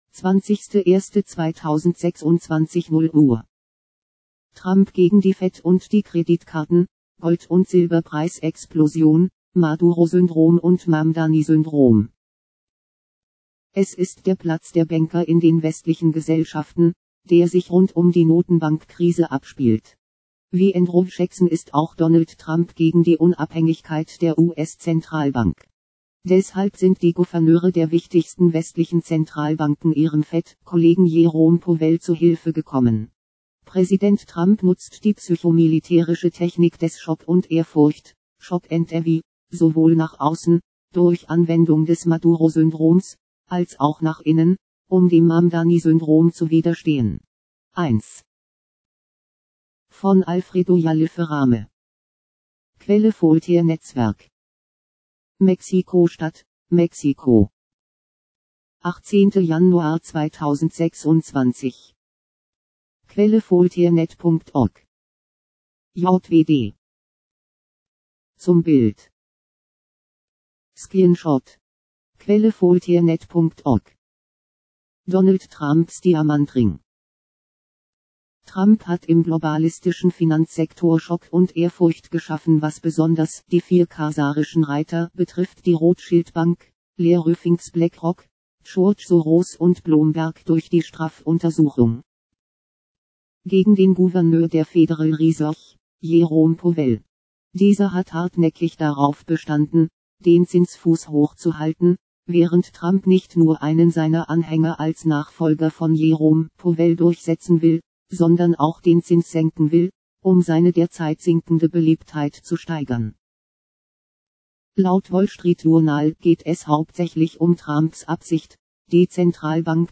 ..vorlesen | Popupfenster öffnen mp3 |  erzeugt mit Pediaphone | JWD